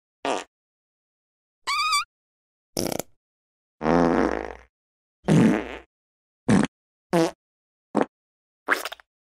Farting Variations